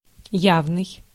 Ääntäminen
Synonyymit crude manifest transparent apparent unabashed demonstrable Ääntäminen US GenAm: IPA : /ˈɑb.viː.əs/ UK : IPA : /ˈɒ(b).viː.əs/ Tuntematon aksentti: IPA : /ˈɑb.vi.əs/ IPA : /ˈɒb.vɪəs/ Lyhenteet ja supistumat obv.